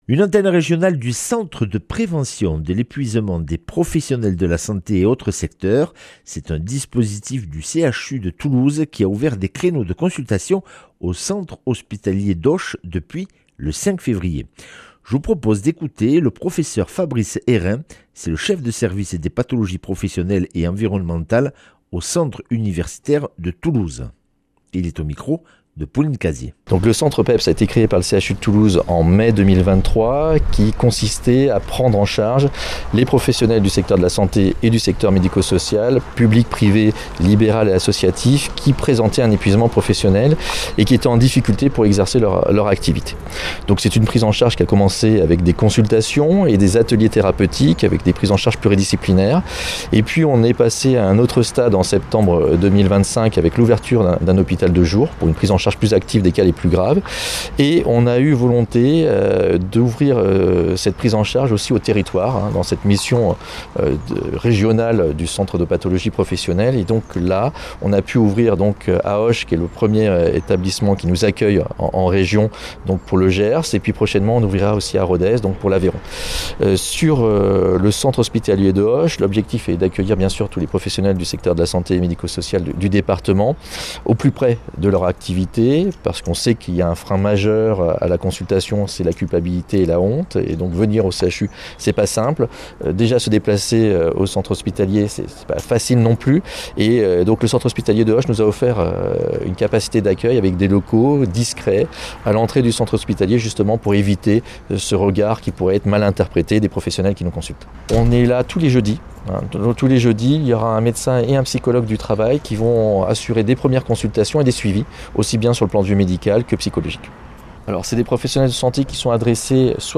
mercredi 22 avril 2026 Interview et reportage Durée 10 min